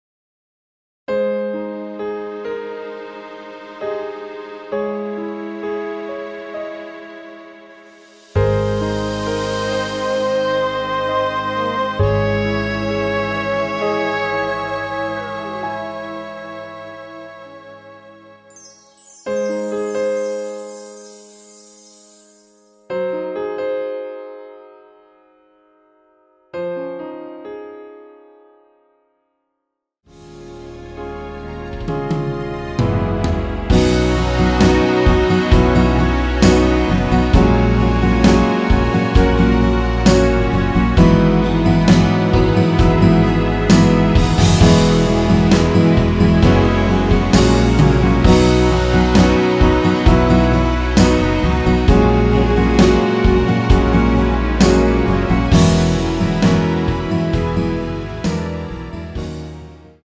Ab
◈ 곡명 옆 (-1)은 반음 내림, (+1)은 반음 올림 입니다.
앞부분30초, 뒷부분30초씩 편집해서 올려 드리고 있습니다.